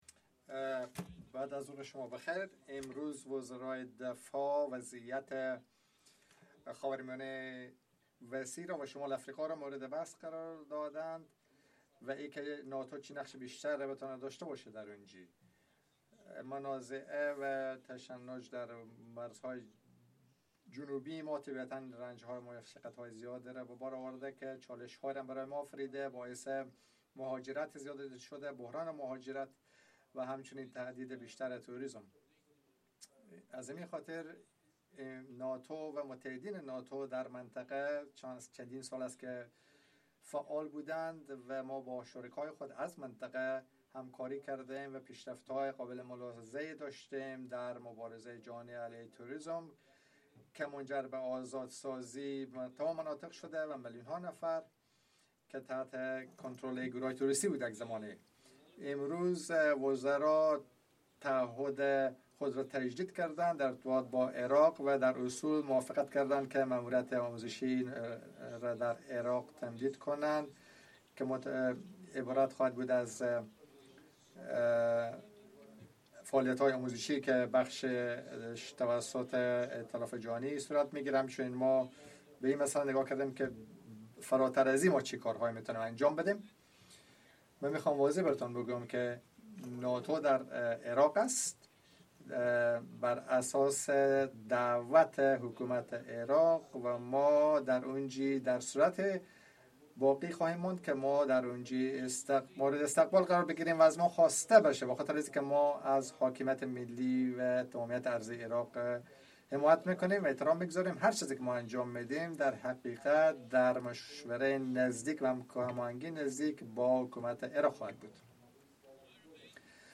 Press conference
by NATO Secretary General Jens Stoltenberg